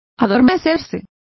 Complete with pronunciation of the translation of drowsed.